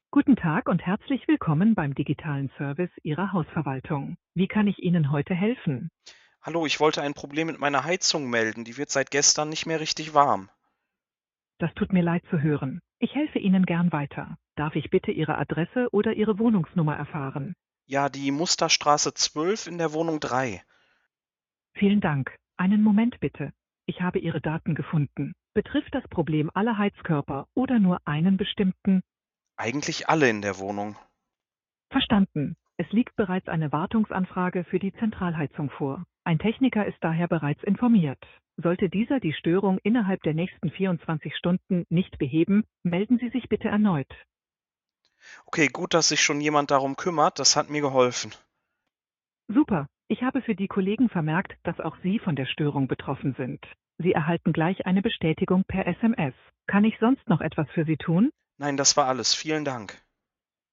Für jeden Fall die passende KI mit der passenden Stimme:
KI-WoWi.mp3